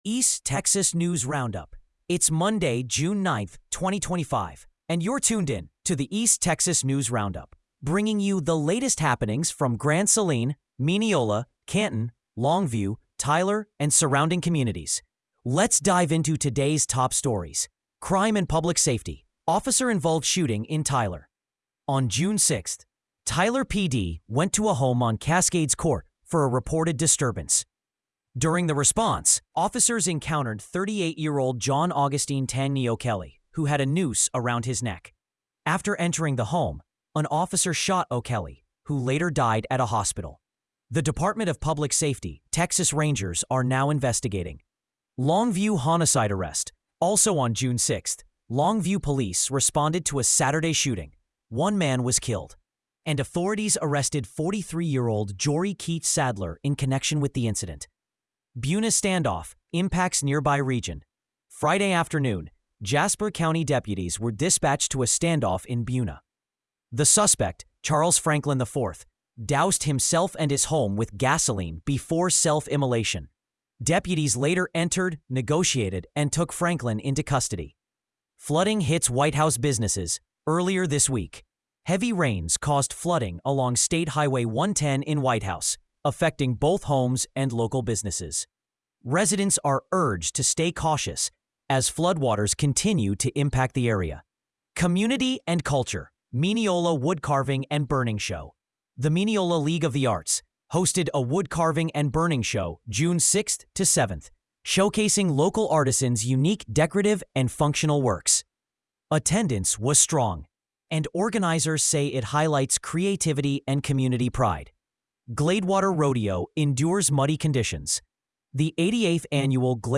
Today’s East Texas news.